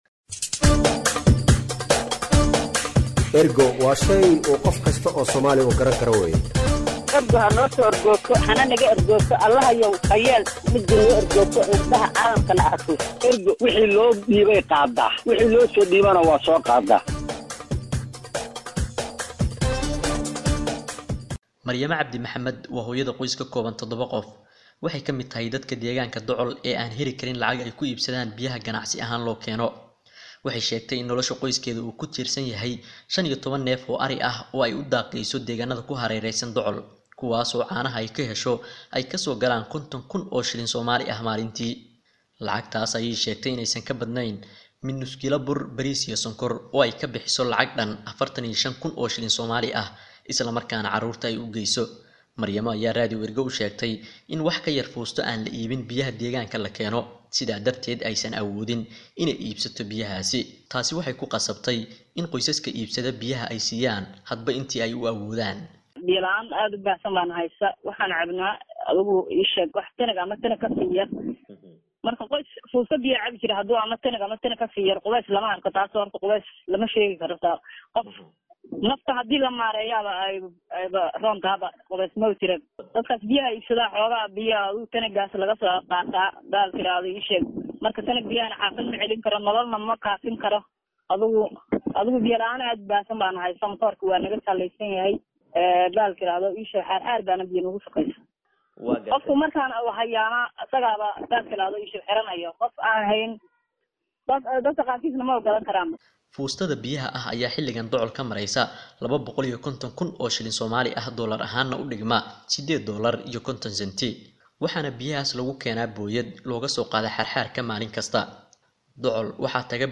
Warbixinta-codeysan-Ee-docol.mp3